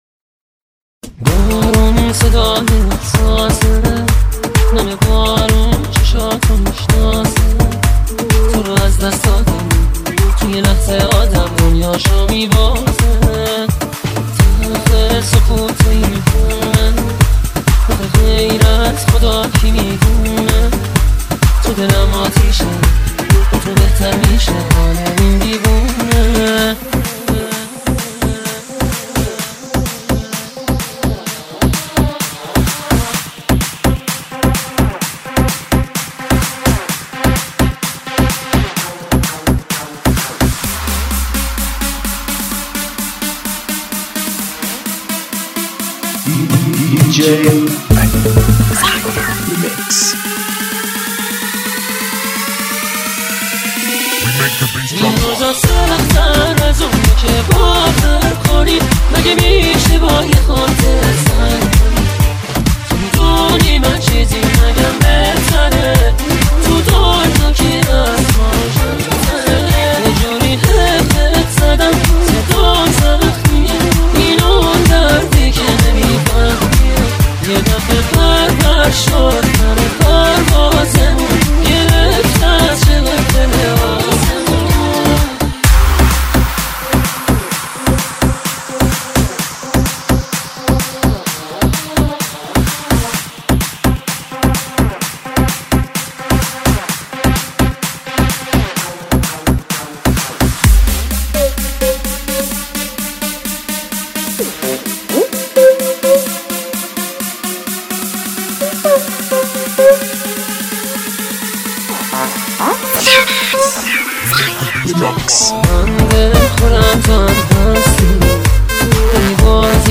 تند بیس دار